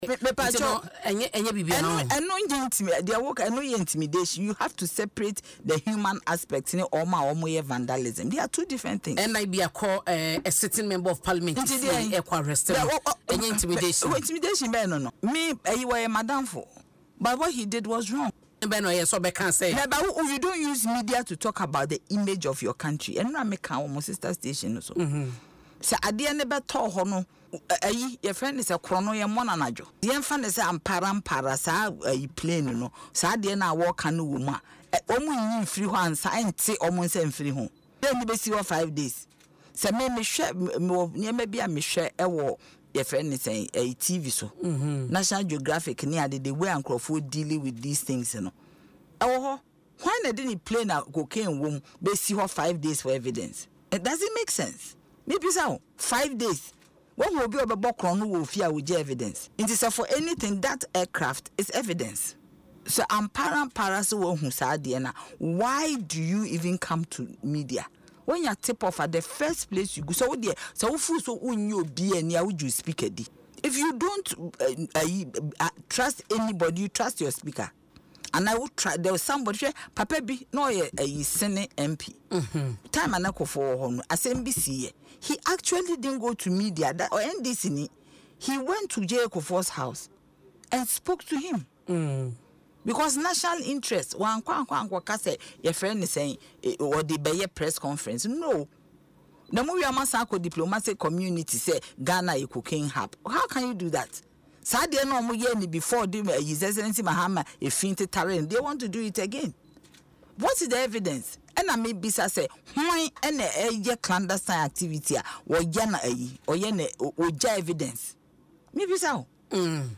If he hasn’t done anything wrong, the truth will eventually come out” she said in an interview on Adom FM’s Dwaso Nsem .